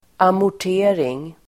Uttal: [amor_t'e:ring]